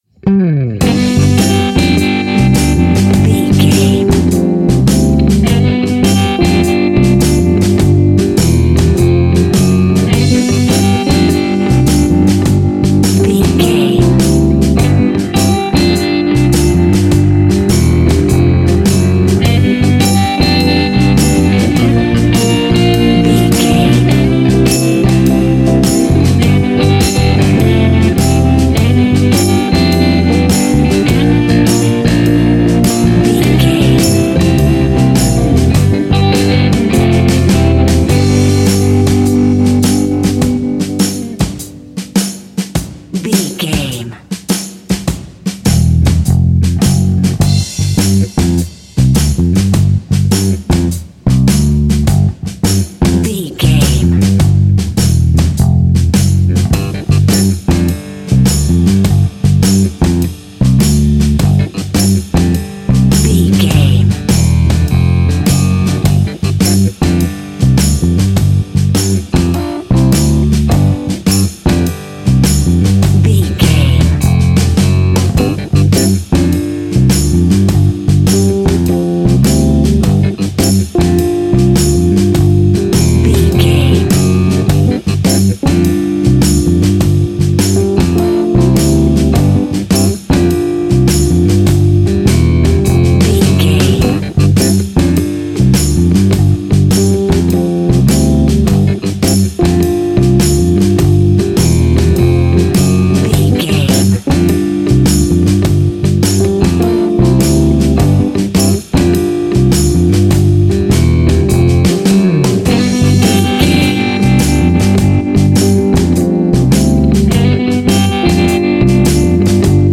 Ionian/Major
funky
uplifting
bass guitar
electric guitar
organ
percussion
drums
saxophone
groovy